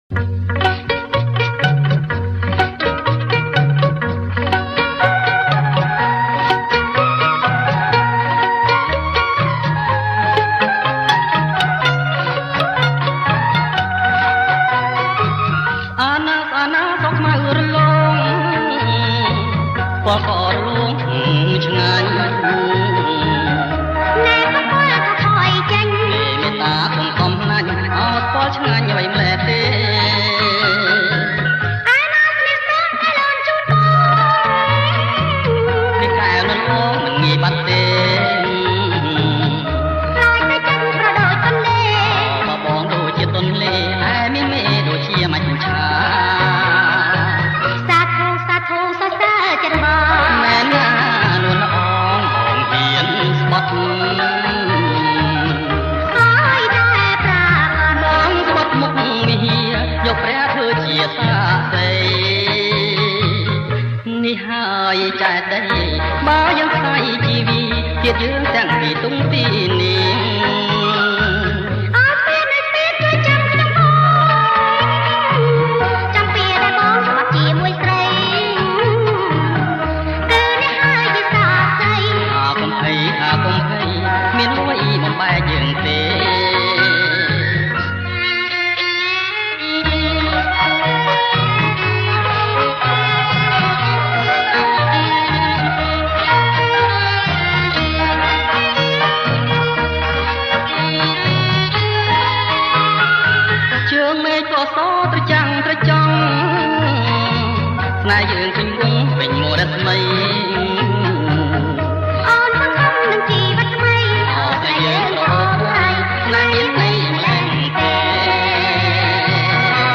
• ប្រគំជាចង្វាក់ Rumba